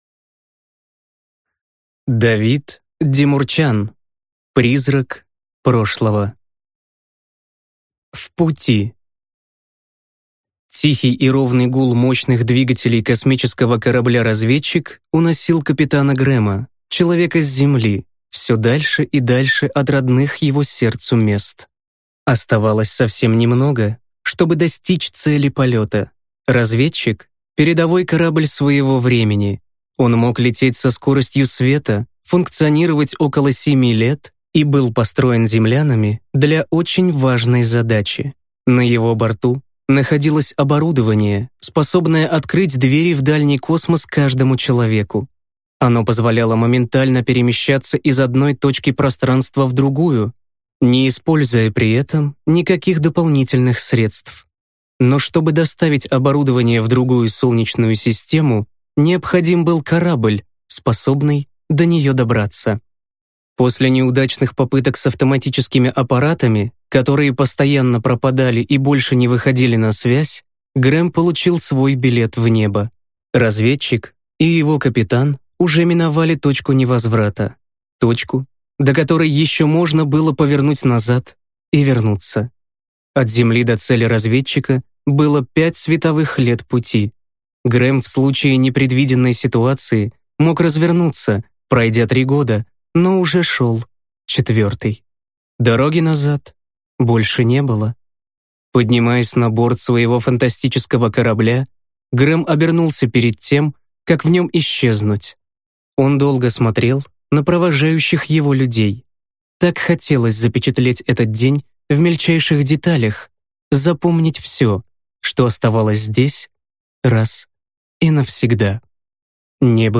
Аудиокнига Призрак прошлого | Библиотека аудиокниг
Прослушать и бесплатно скачать фрагмент аудиокниги